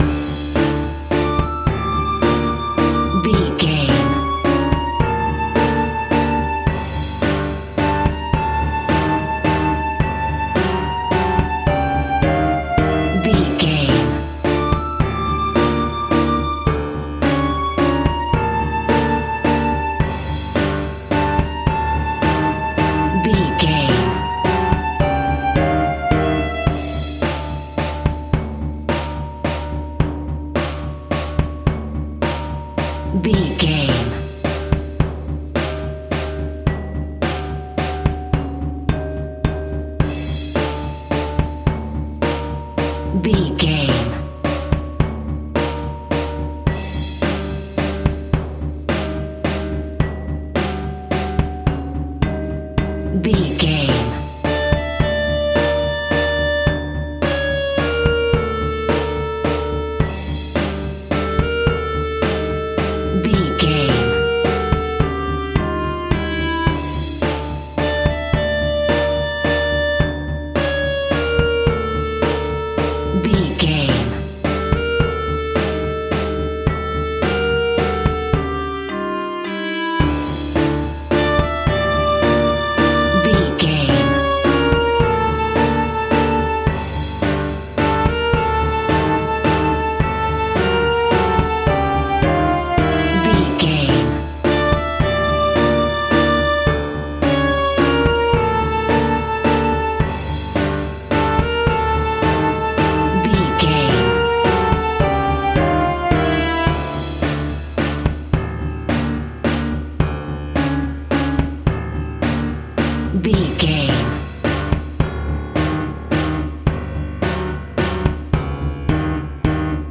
Clown Horror.
Aeolian/Minor
scary
tension
ominous
dark
suspense
eerie
flute
drums
percussion
piano
synth
pads